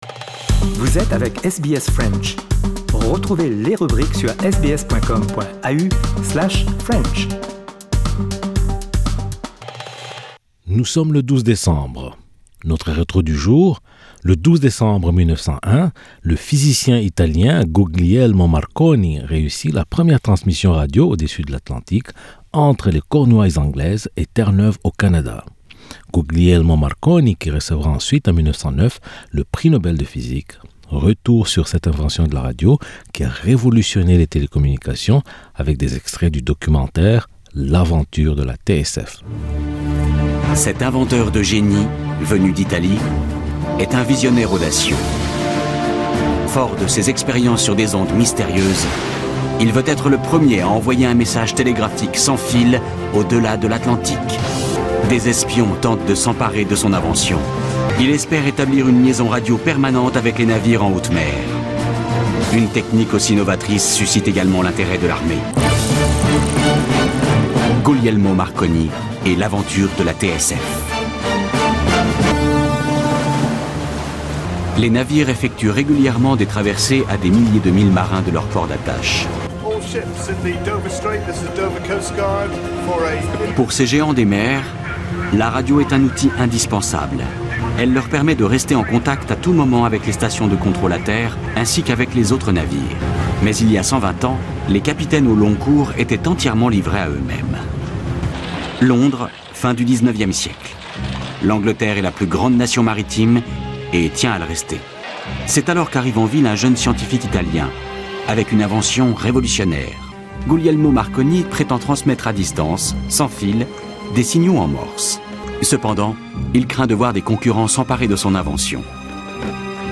Retour sur cette invention de la radio... qui a révolutionné les télécommunications avec des extraits du documentaire…"L'aventure de la TSF"...